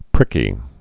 (prĭkē)